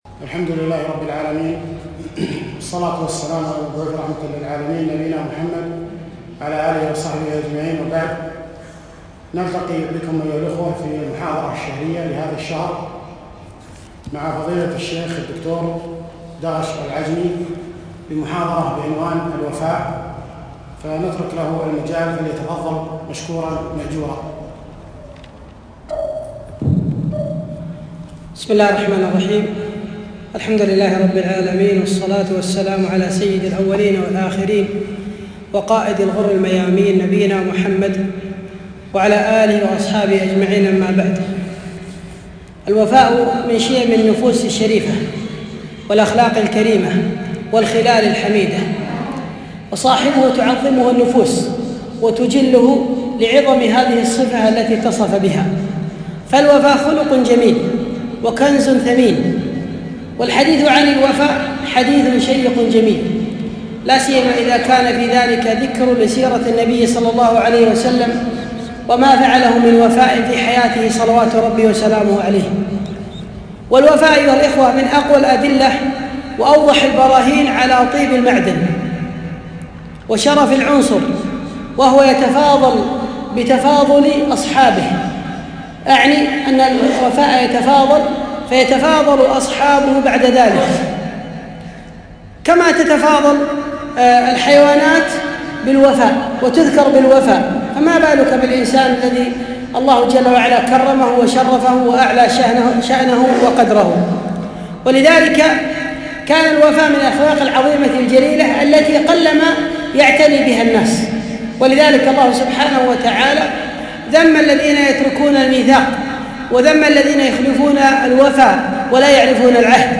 محاضرة - الــوفــاء - دروس الكويت